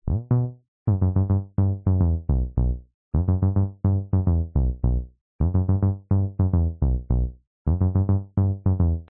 标签： 贝斯 寒冷 hip_hop 器乐 流行 trip_hop
声道立体声